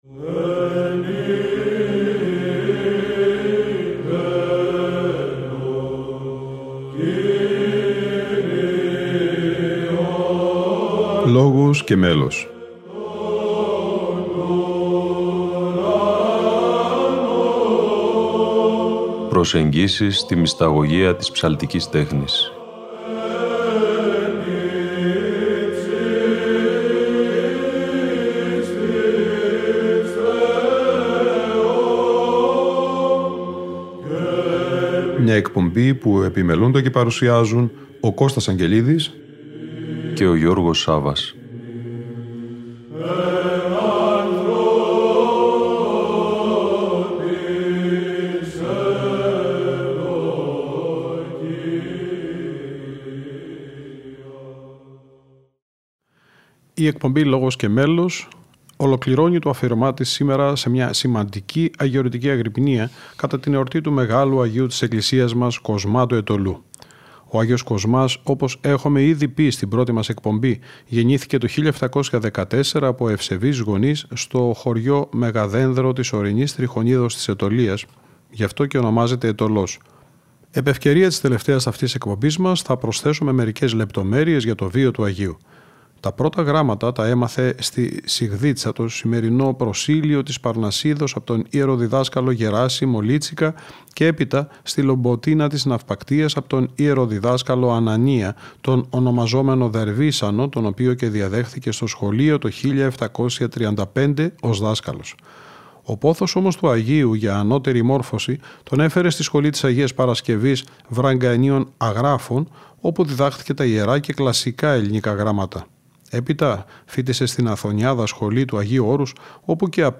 Αγρυπνία Αγ. Κοσμά Αιτωλού - Ι. Μ. Φιλοθέου 2009 (ΣΤ΄)